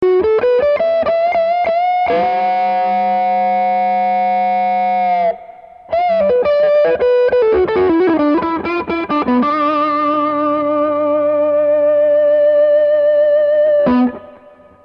Semi-Clean